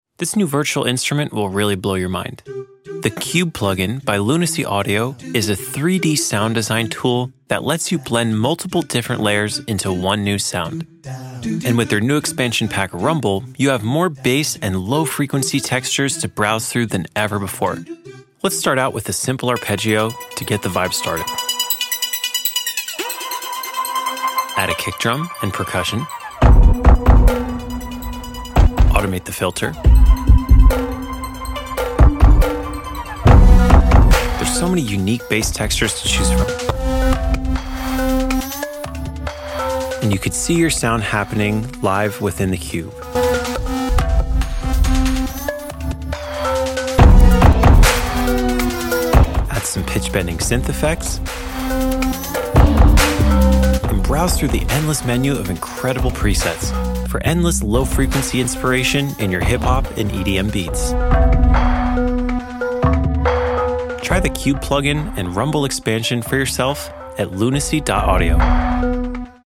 Cube plugin just got even better with the Rumble Expansion for 3D Bass in your Hip Hop & EDM productions 🔥